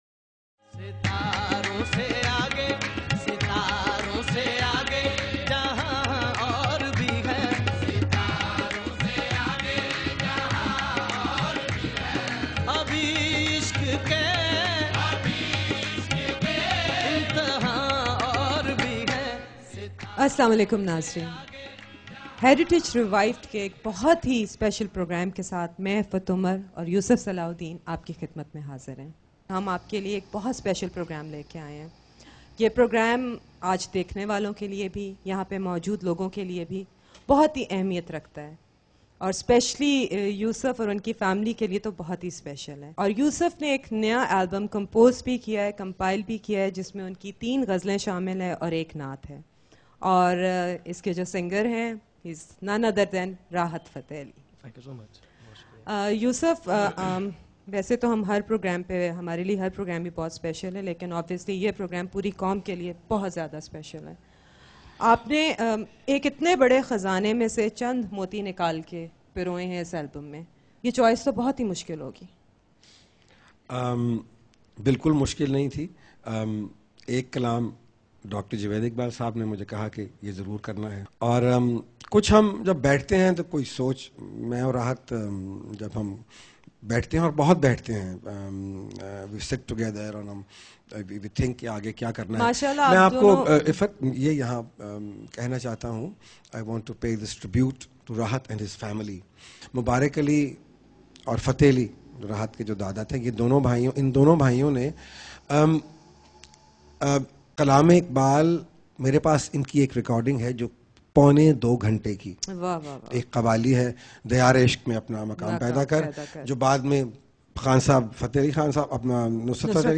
Urdu Qawwali and Sufiana Kalam